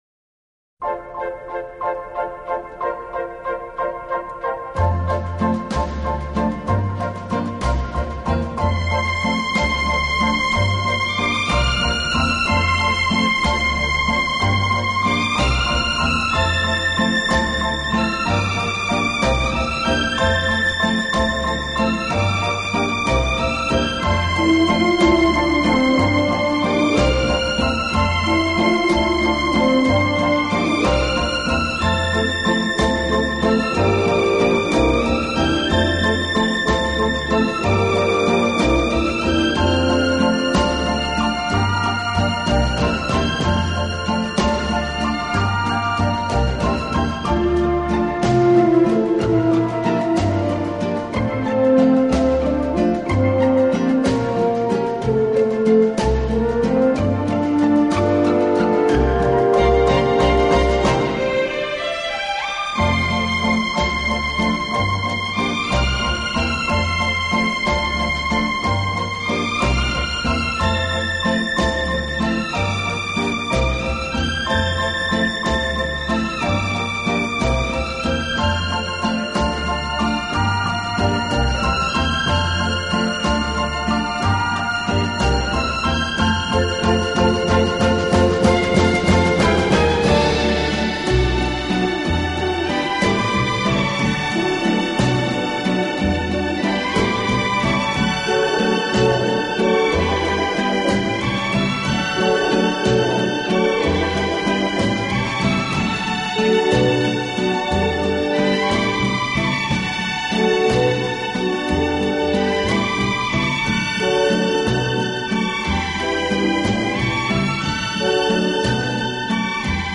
轻音乐
轻音乐专辑